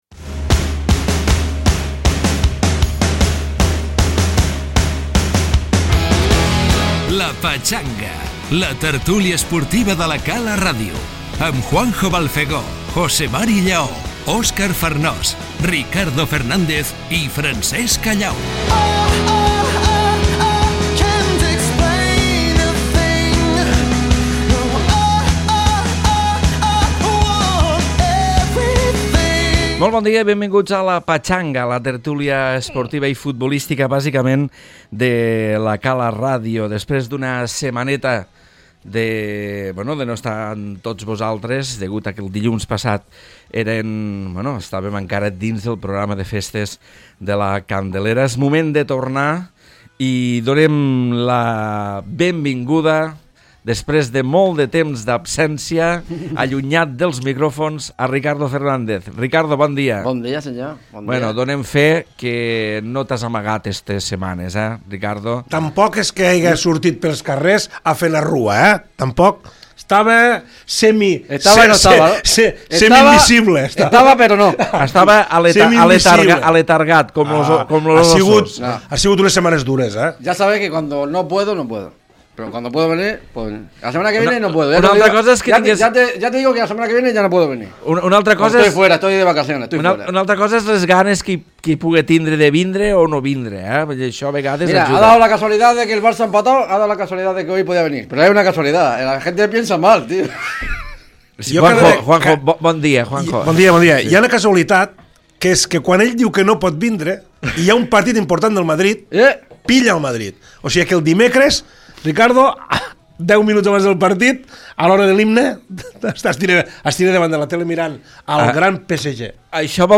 Tertúlia futbolística dels dilluns on repassem els partits del Barça i el Madrid, ja que La Cala i la resta d’equips regionals han descansat aquest cap de setmana.